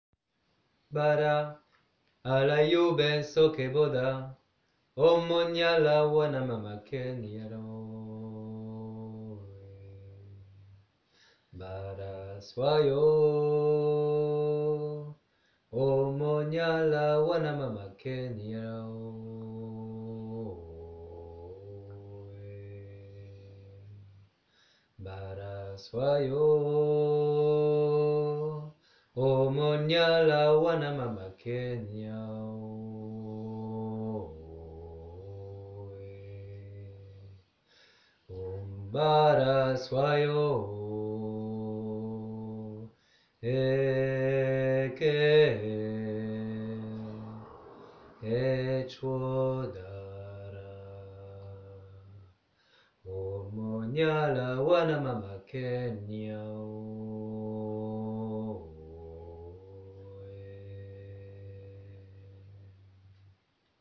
barasuayo basse.m4a
barasuayo-basse.m4a